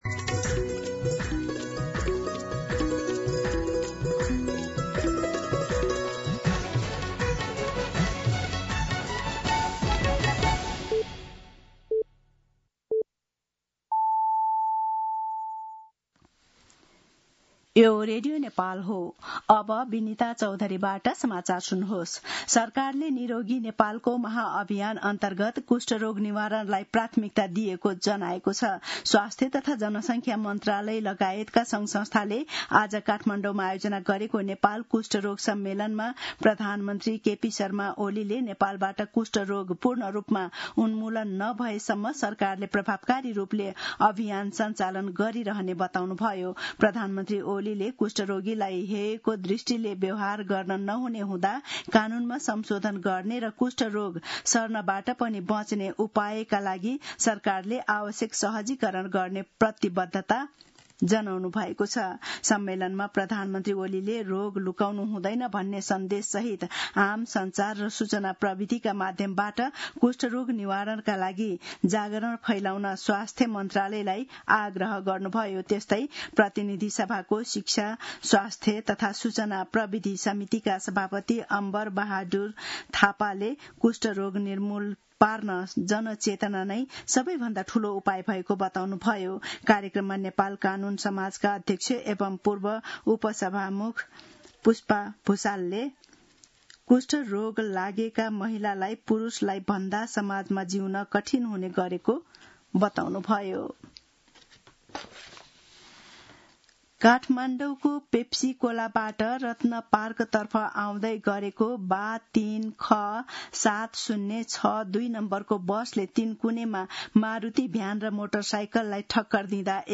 मध्यान्ह १२ बजेको नेपाली समाचार : २५ वैशाख , २०८२
12-pm-Nepali-News-1.mp3